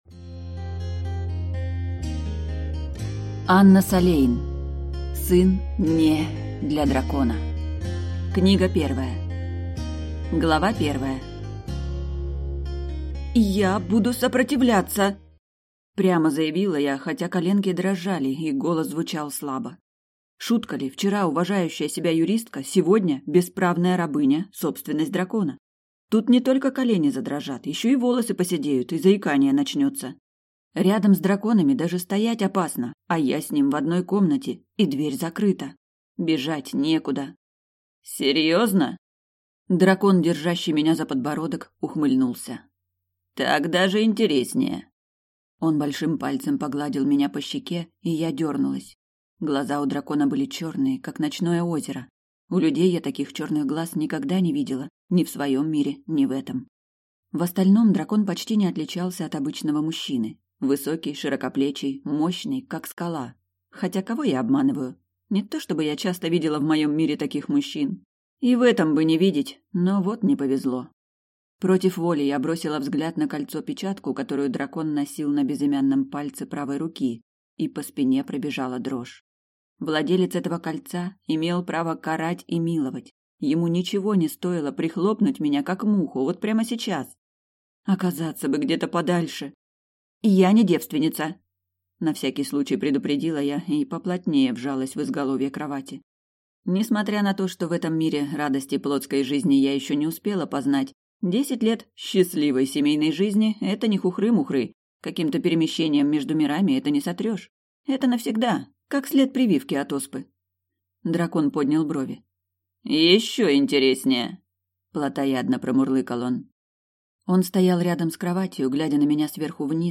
Аудиокнига Сын (не) для дракона. Книга 1 | Библиотека аудиокниг